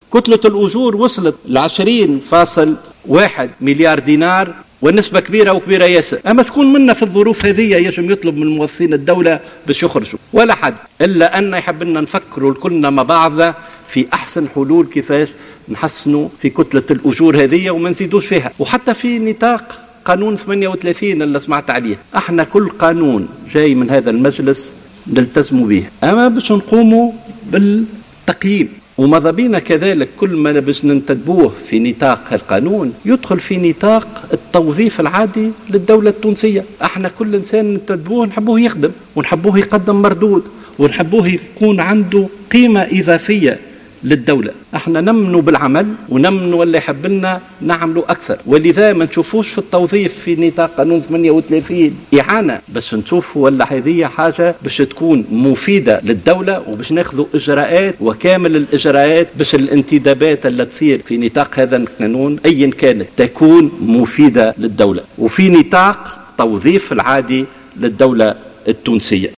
قال وزير الاقتصاد والمالية علي الكعلي في مداخلته اليوم الأحد خلال الجلسة العامة المخصصة لمناقشة مشروع قانون المالية لسنة 2021، إن تونس تعيش وضعا صعبا ومن المهم أن تتعاضد كل المجهودات من أجل مصلحة البلاد.